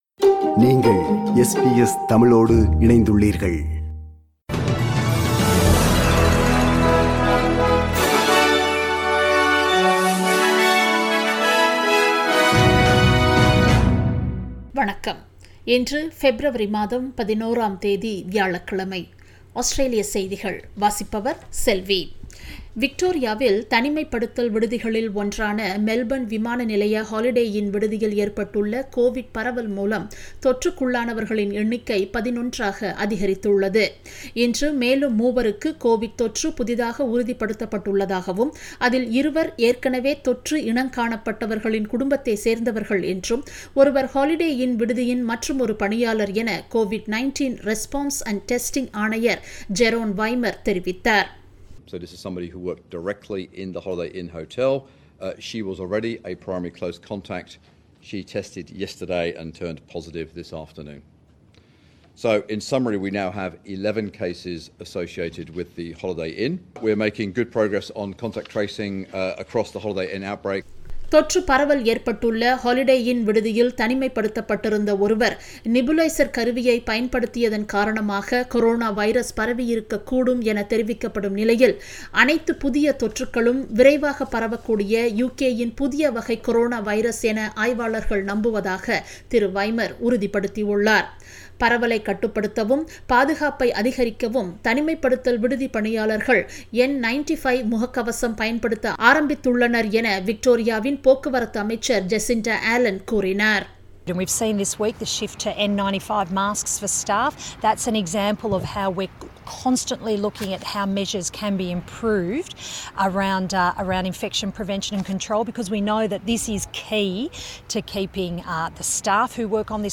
Australian news bulletin for Thursday 11 February 2021.